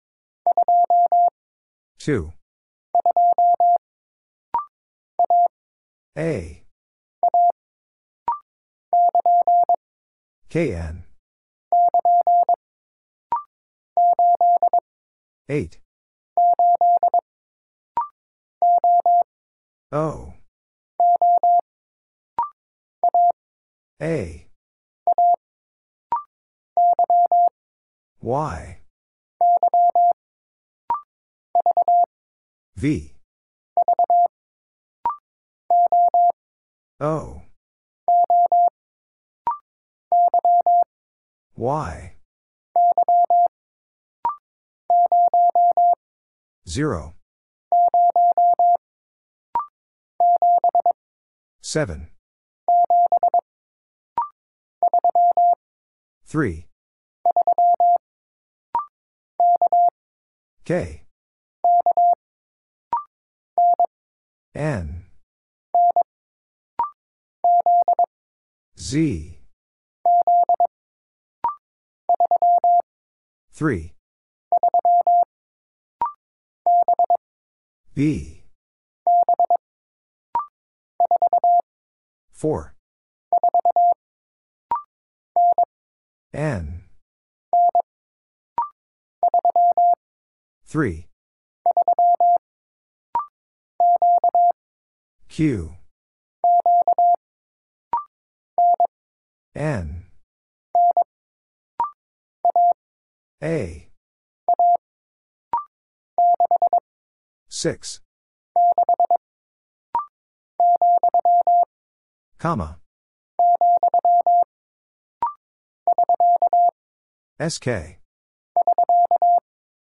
The time is very short between the Morse code and hearing the answer spoken.
First, it is sent in Morse code. Then it is spoken. And finally, it is sent again in More code.